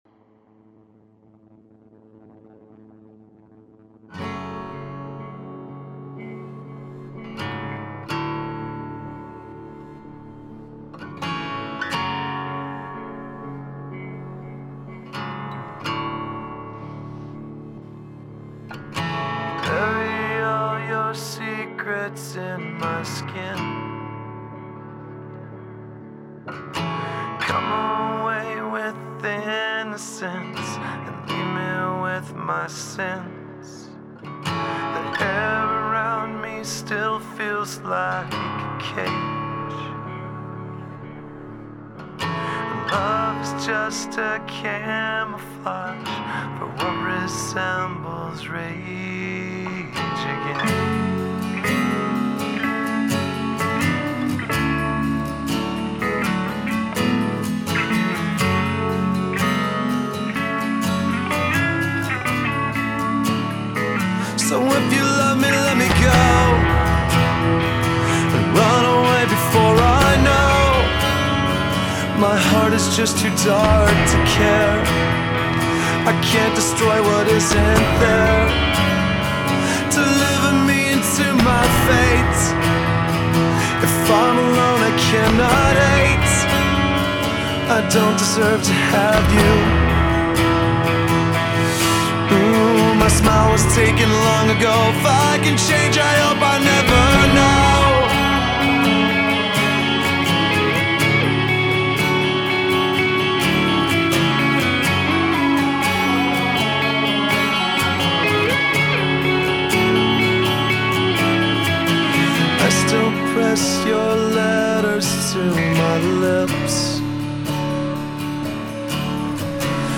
ژانر : آلترنیتیو متال